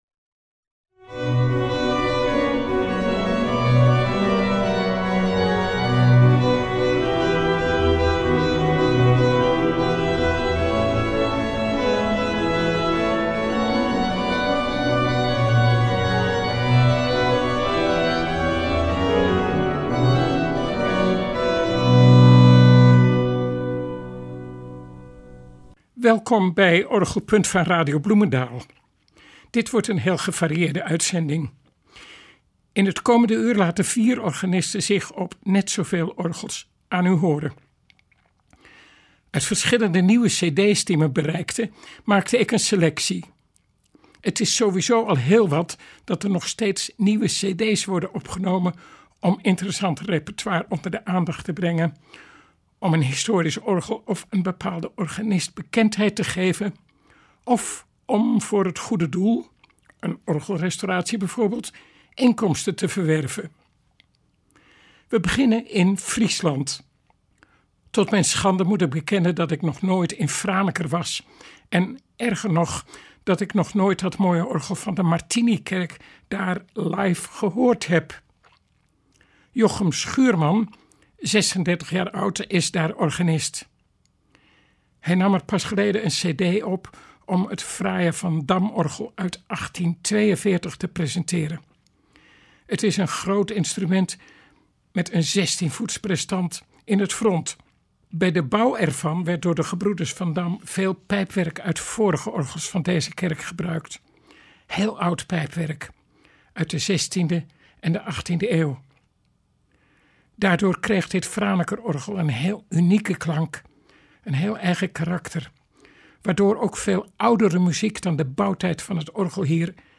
opnamen horen van het Van Dam-orgel (afbeelding boven) in de Martinikerk te Franeker